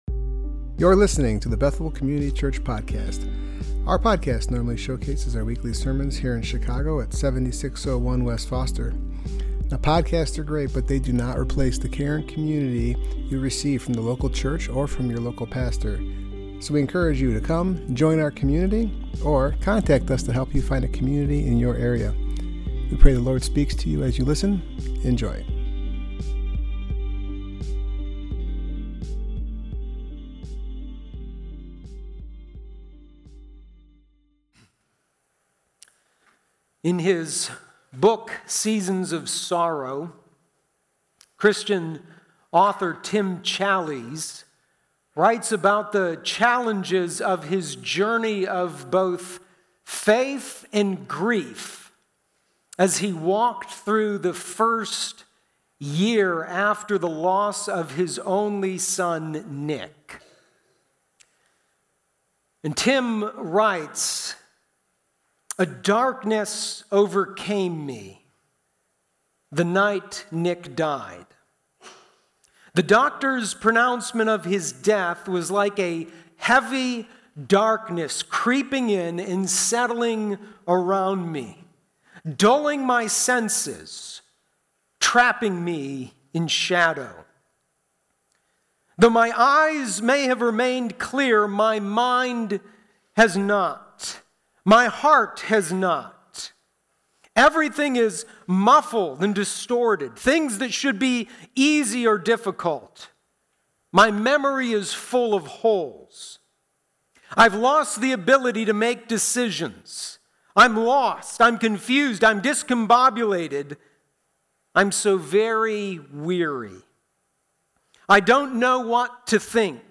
Service Type: Worship Gathering Topics: lamenting , pain , Shame , sorrow , suffering , waiting